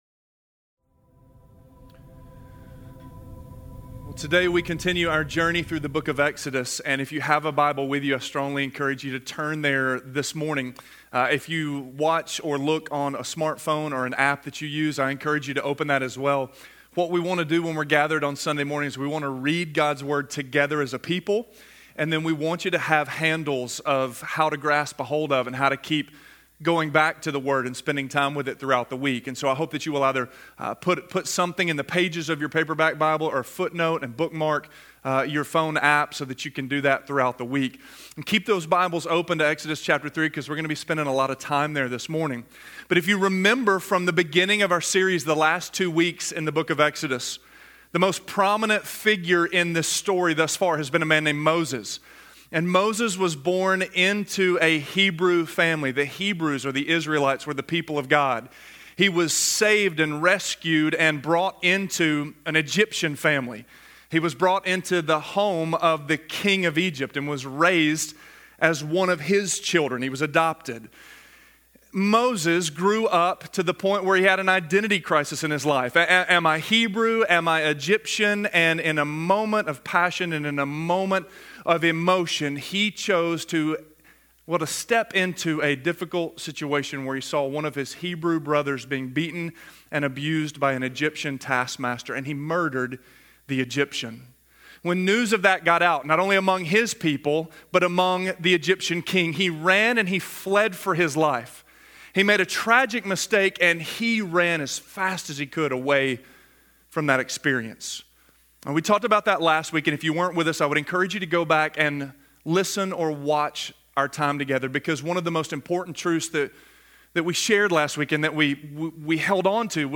The Evacuation Initiated: Moses Called - Sermon - Avenue South